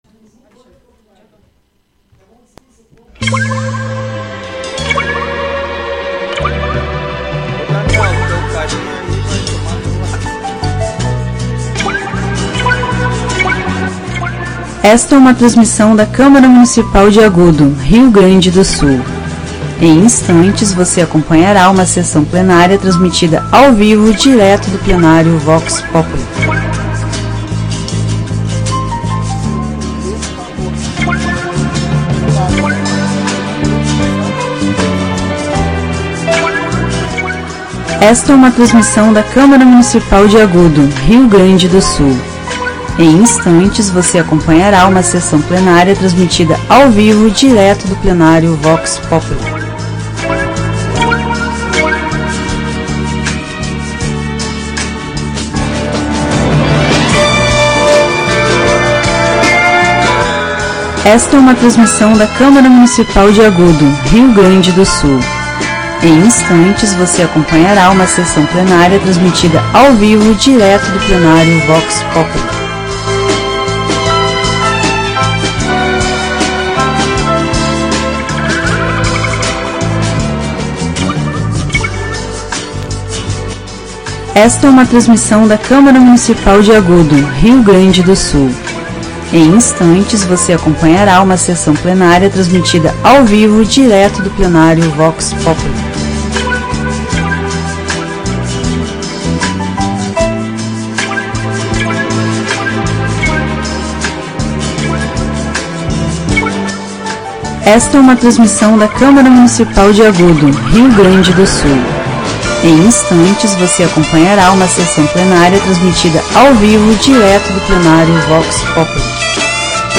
Áudio da 39ª Sessão Plenária Extraordinária da 17ª Legislatura, de 09 de março de 2026